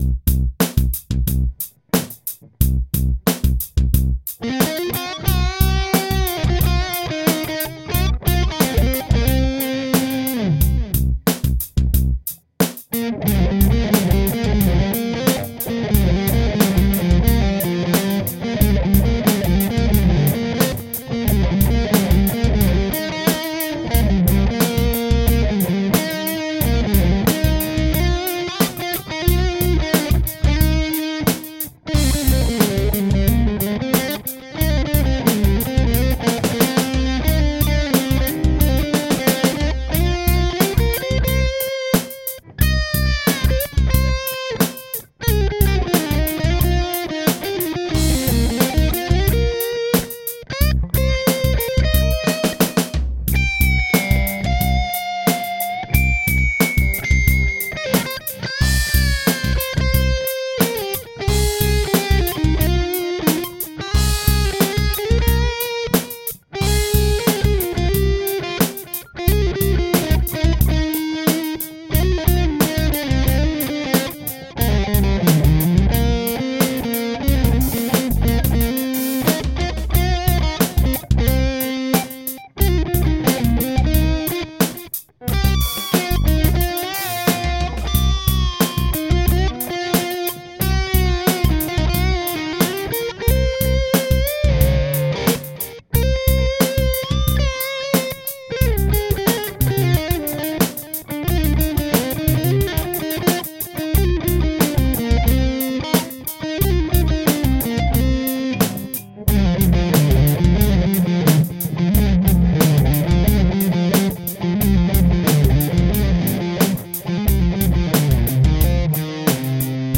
First I recorded the rhythm using the (very basic) built-in rhythm stuff, then I soloed over it. There's no real melody or anything, it's basically just some (hopefully) nice noodling ?